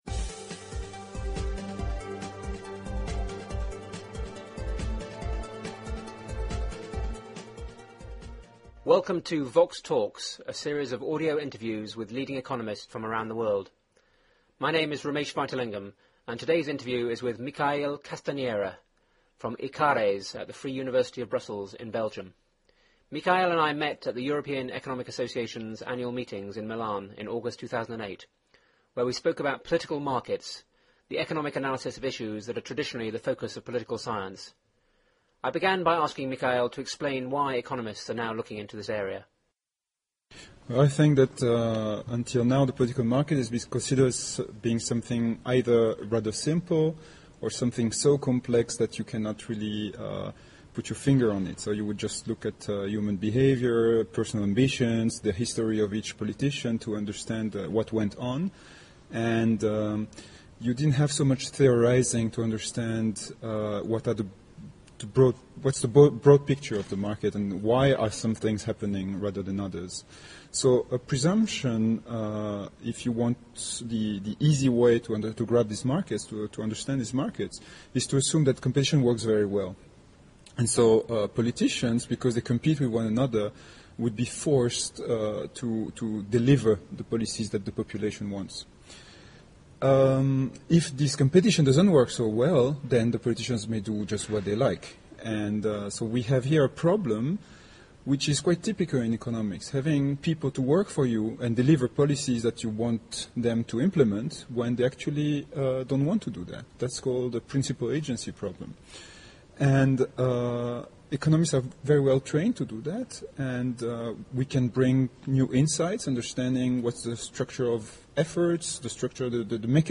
The interview was recorded at the annual congress of the European Economic Association in Milan in August 2008.